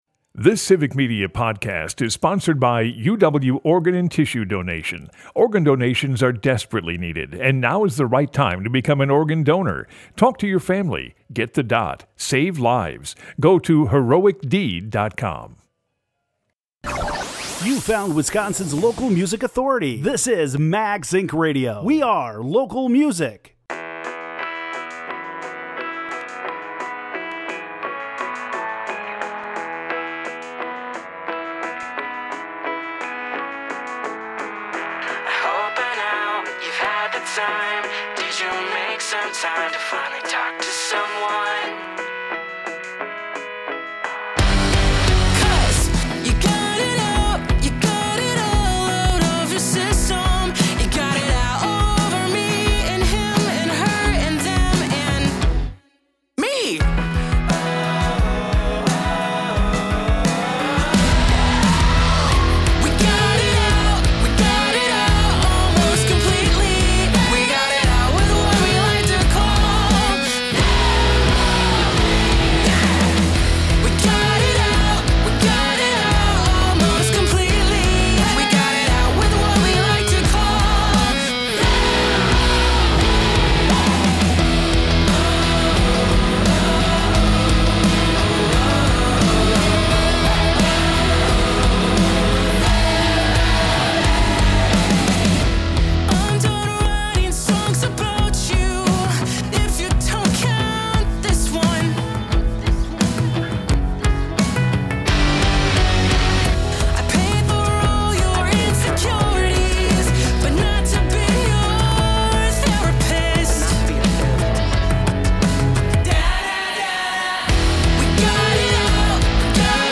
spinning Wisconsin Americana, Country, Bluegrass, Folk and both regional and national artists coming through Wisconsin.